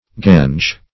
Meaning of gange. gange synonyms, pronunciation, spelling and more from Free Dictionary.
Search Result for " gange" : The Collaborative International Dictionary of English v.0.48: Gange \Gange\ (g[a^]nj), v. t. [imp.